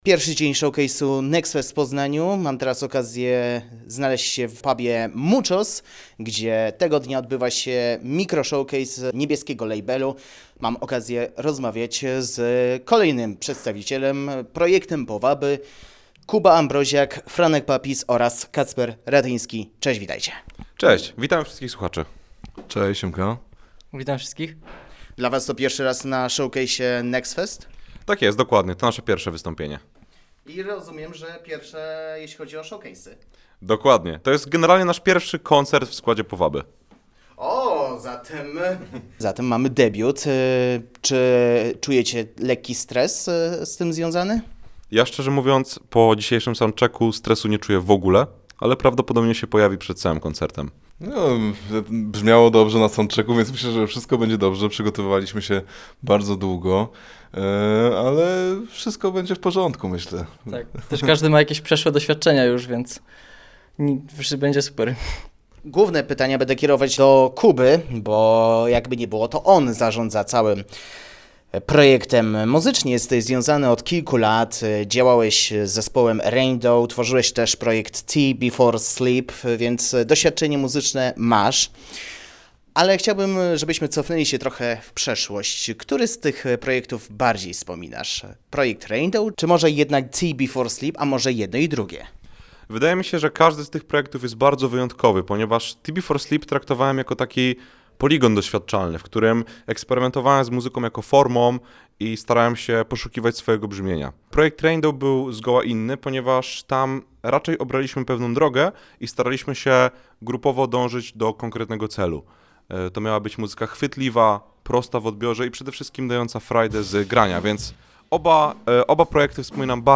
Pierwszego dnia tegorocznej edycji Next Festu zagrali debiutancki koncert